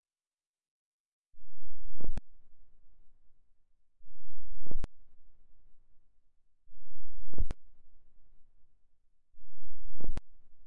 Loops The Cube 90bpm " The cube LFO